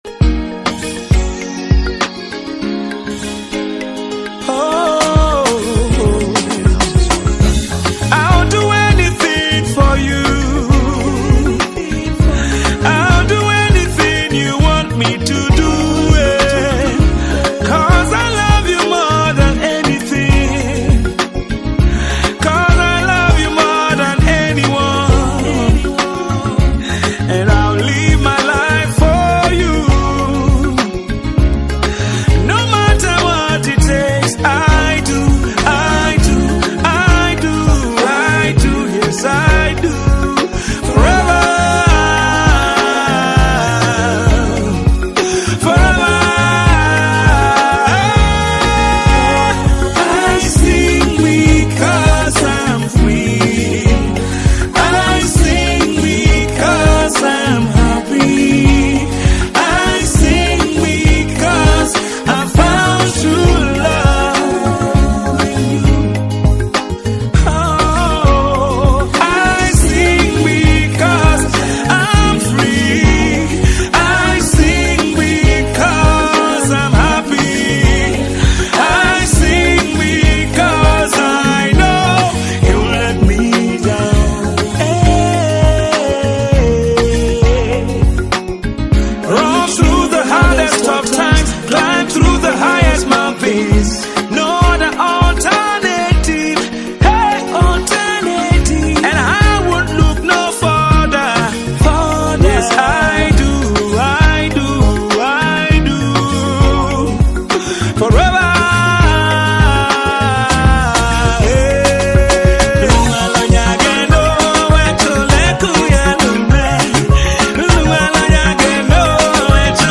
Tiv songs
is encouraging, uplifts the spirit and soul